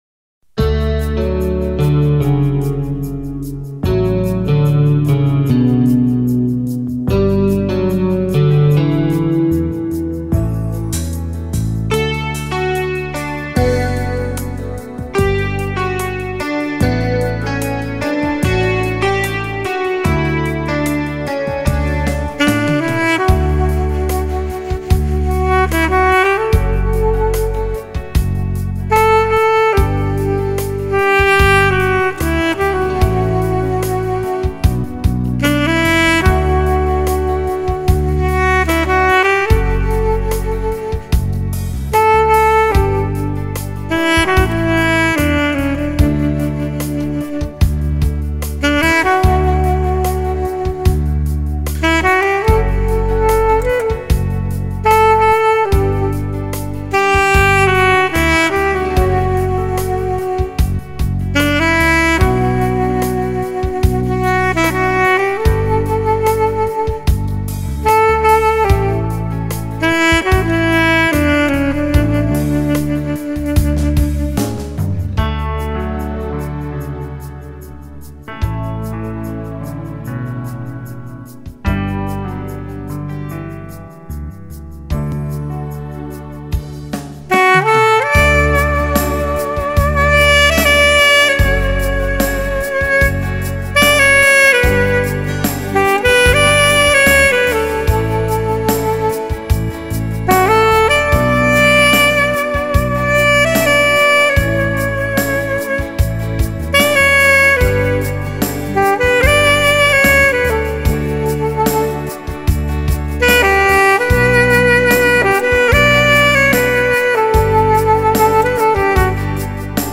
Very soulful
instrumental songs of praise and worship
saxophone